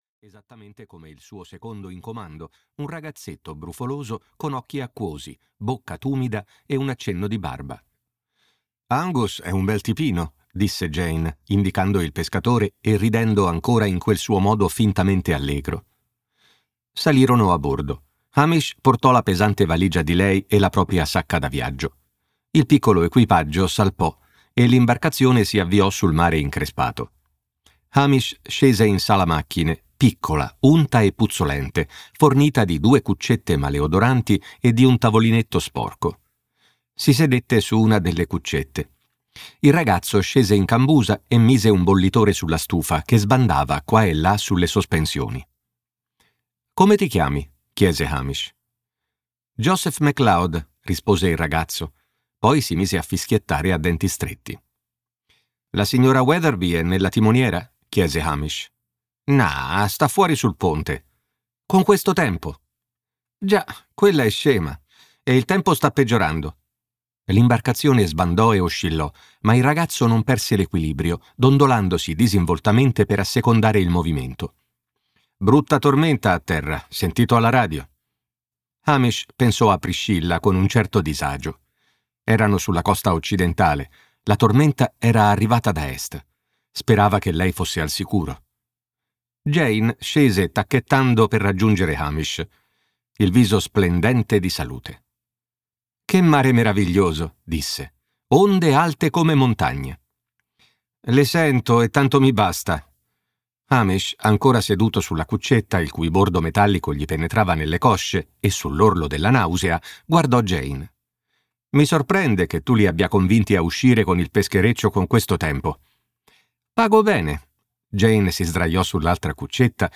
"Morte di una snob" di M.C. Beaton - Audiolibro digitale - AUDIOLIBRI LIQUIDI - Il Libraio